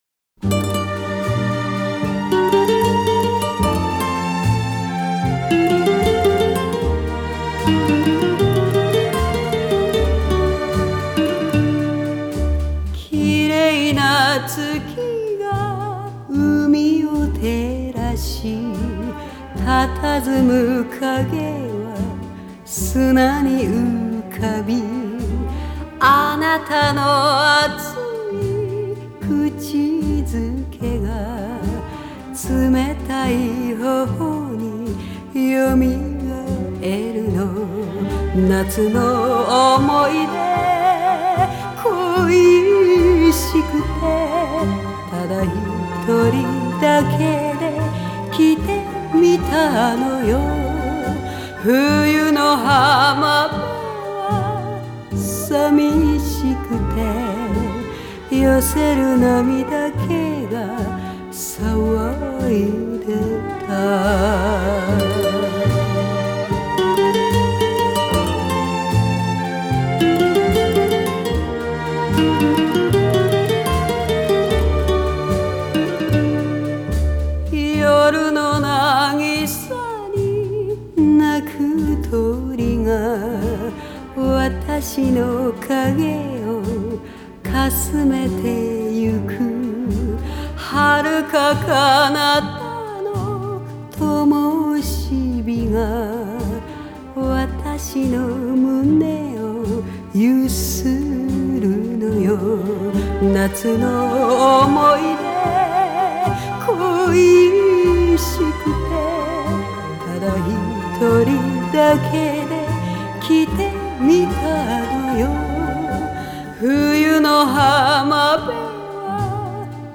Обе песни исполняют певицы.